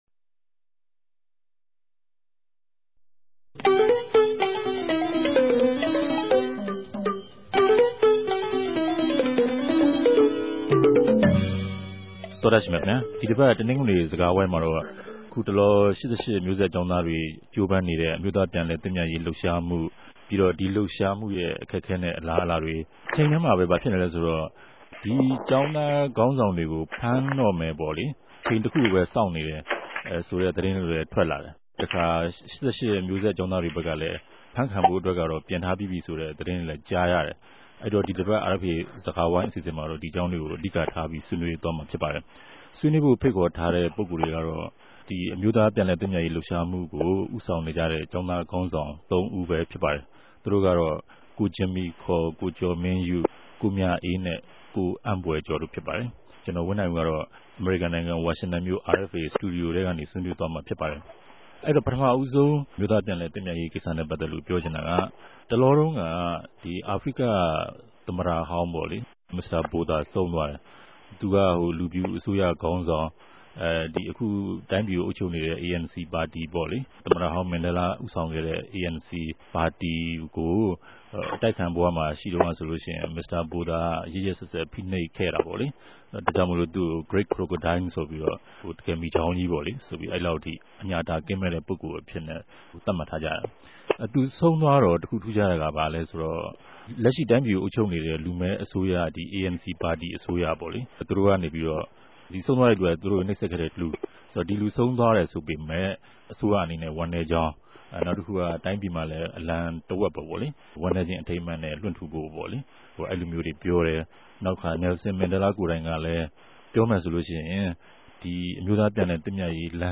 ဒီတပတ်မြာ ပၝဝင်ဆြေးေိံြးုကမယ့် ပုဂ္ဂြိလ်တေကြတော့ အမဵိြးသား ူပန်လည်သင့်ူမတ်ရေး လြပ်ရြားမကြို ဦးဆောင်ူပလြုပ်နေုကတဲ့ ကေဵာင်းသား ခေၝင်းဆောင် သုံးဦးပဲူဖစ်ပၝတယ်။